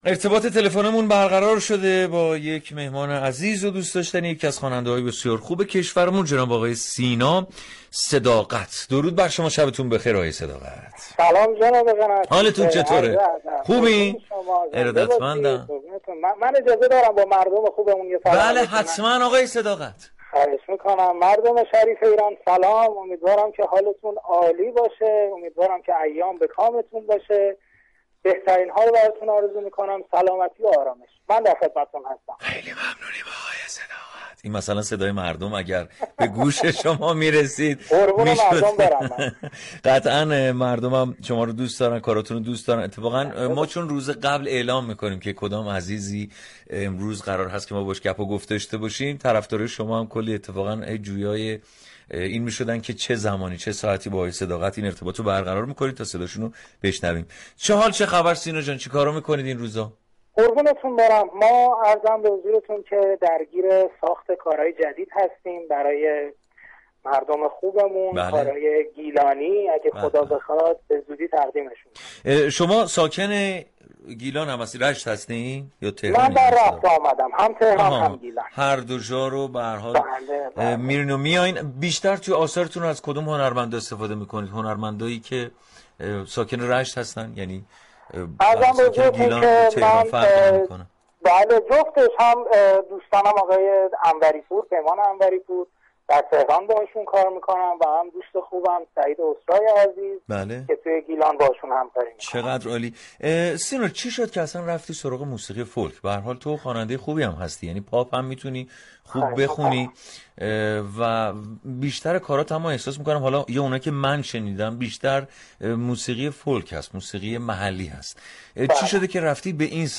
دریافت فایل به گزارش روابط عمومی رادیو صبا برنامه موسیقی محور صباهنگ هر روز با پخش موسیقی های درخواستی مخاطبان از رادیو صبا پخش می شود.